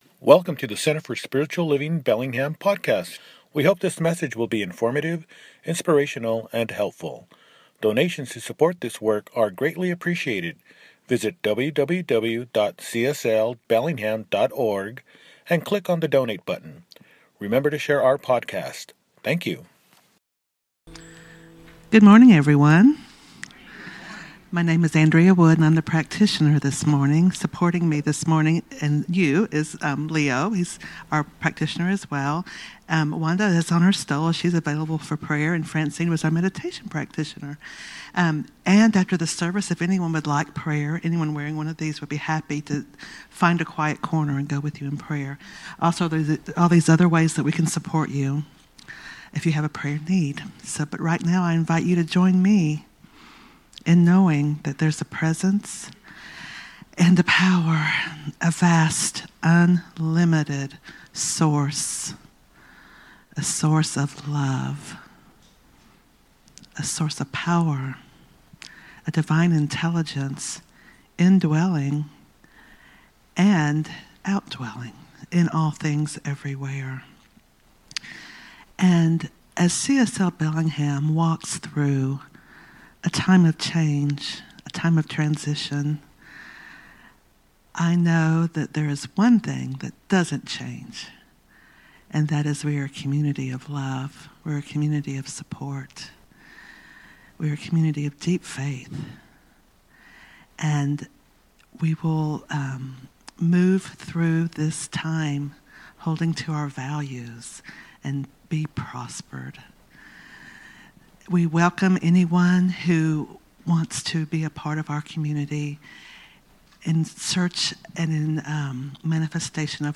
The Roots of True Prosperity– Celebration Service